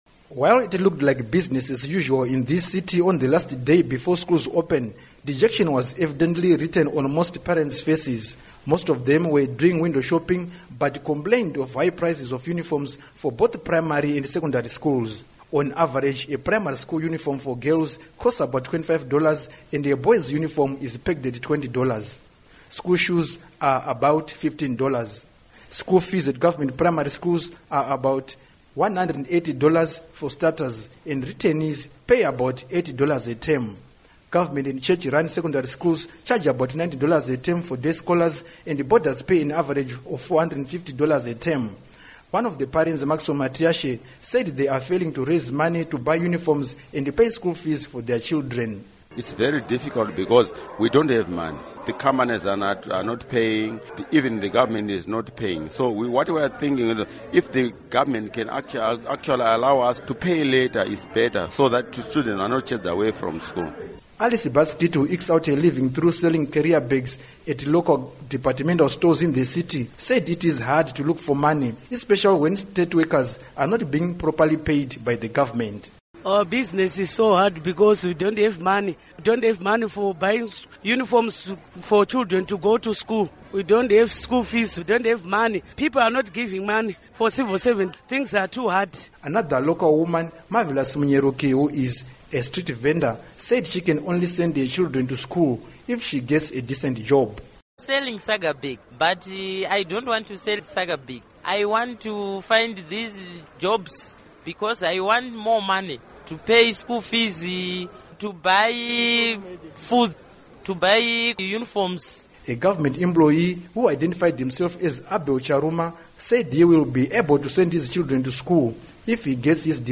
Report on School Fees